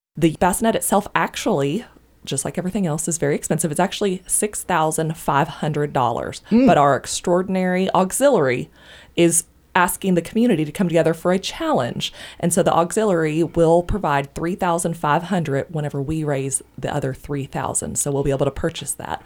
March 12, 2026 5:20 am Local News, WireReady